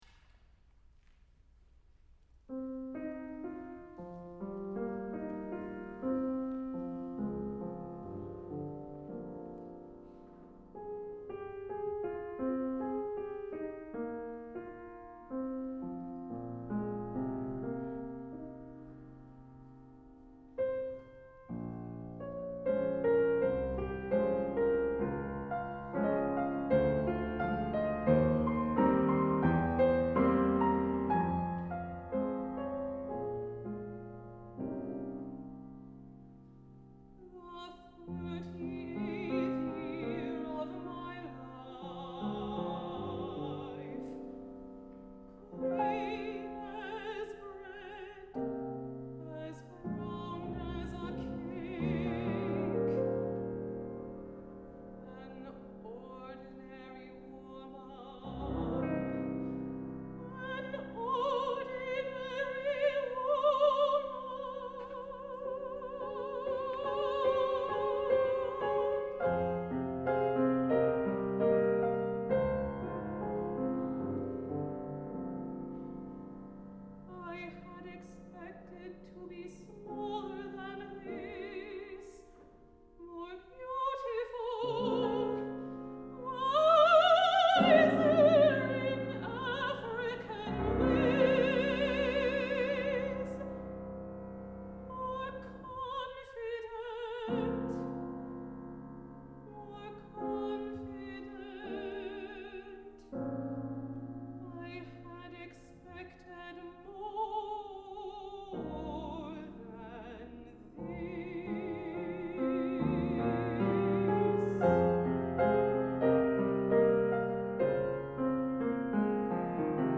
for Mezzo-soprano and Piano (1997)